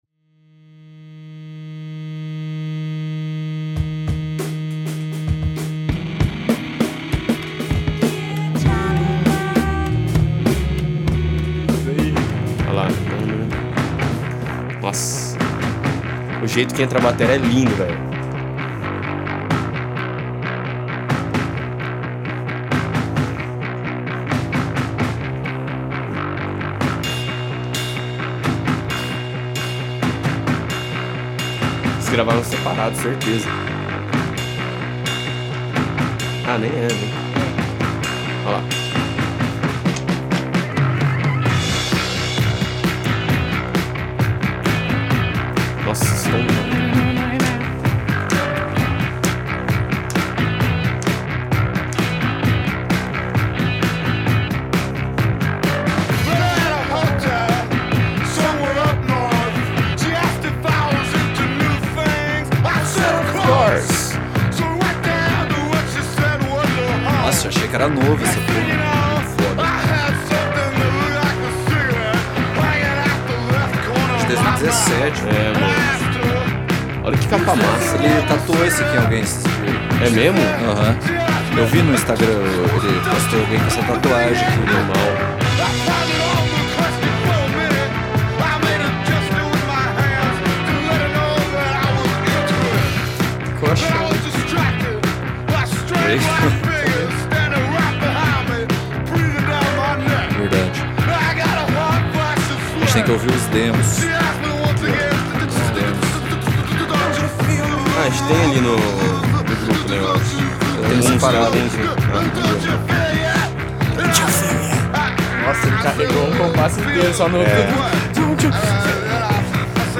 Como ponto de início de um processo criativo a escolha dos diversos esboços pode ser um bom ponto de partida. Em meio a riffs e gravações caseiras, neste episódio o ouvinte participa da decisão do grupo de selecionar algumas dessas proto-músicas para serem lapidadas e melhor estruturadas.